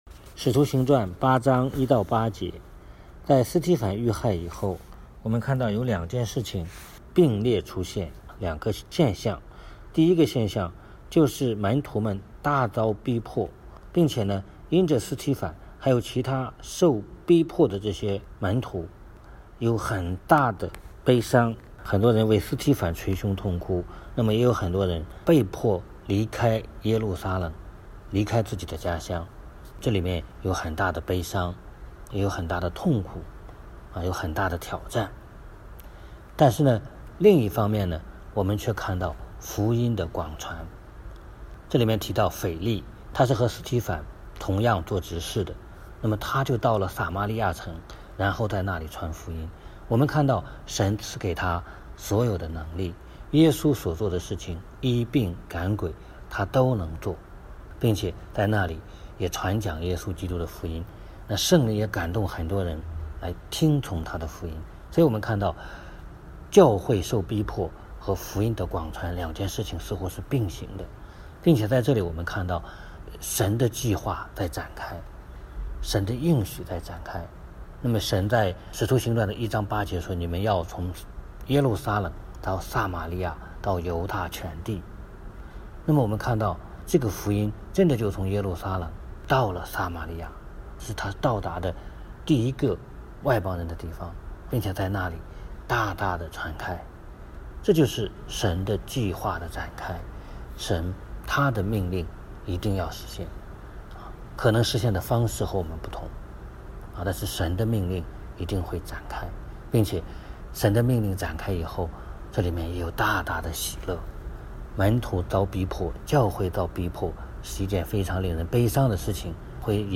圣经学习